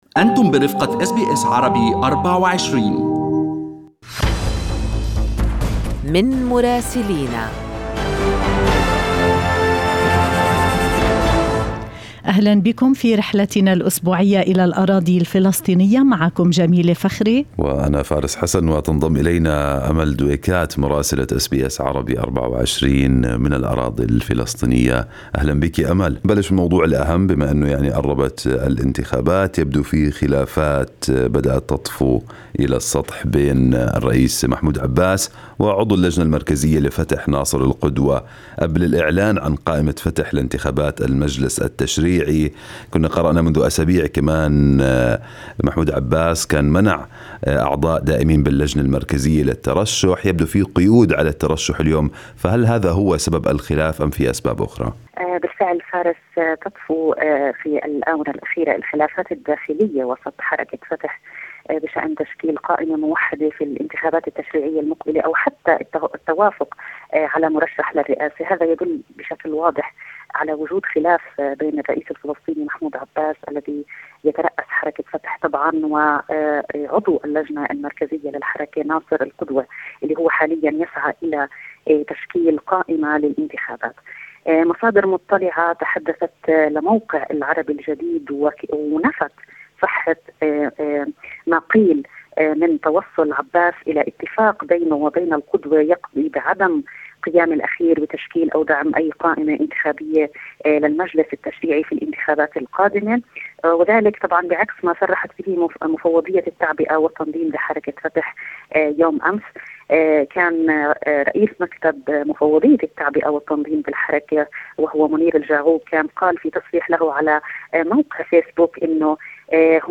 يمكنكم الاستماع إلى تقرير مراسلتنا في رام الله بالضغط على التسجيل الصوتي أعلاه.